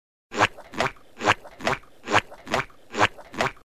Squidward Walking Meme Effect sound effects free download